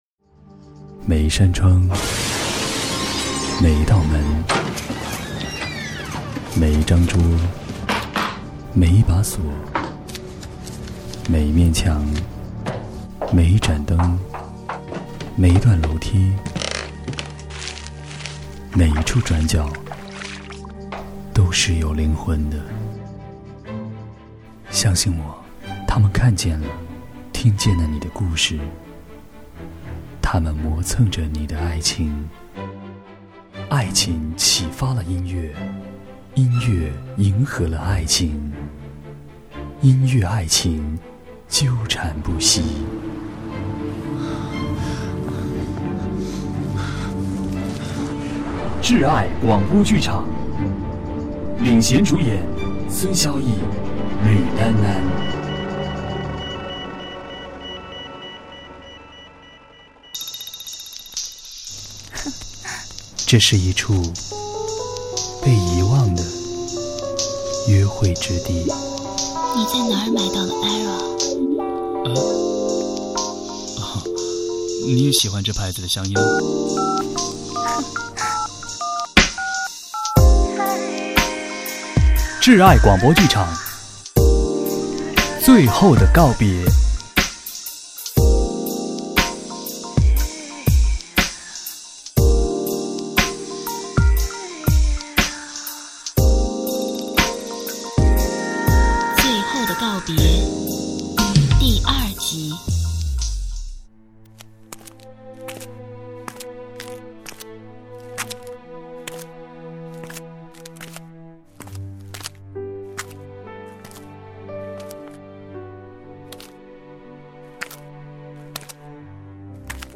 【广播剧】《Good bye at last》最后的告别（2集连播）